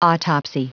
Prononciation du mot autopsy en anglais (fichier audio)
Prononciation du mot : autopsy